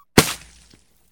slime.ogg